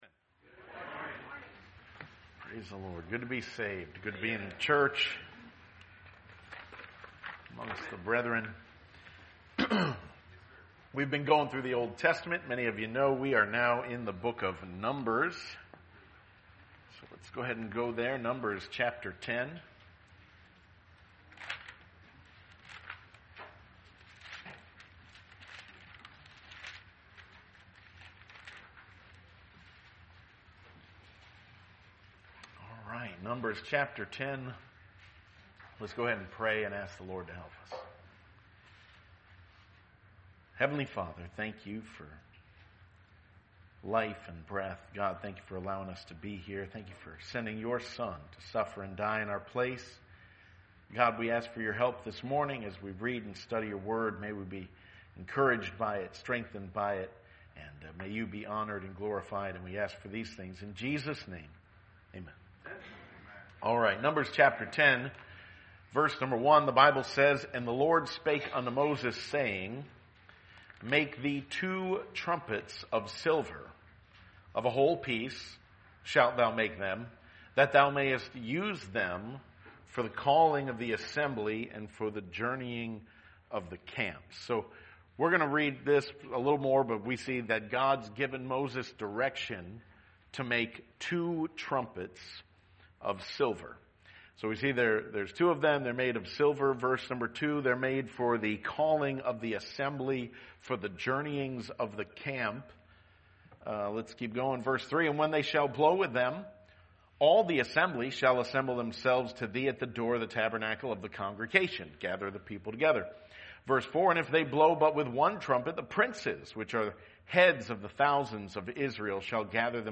Numbers 10: The Two Trumpets | Sunday School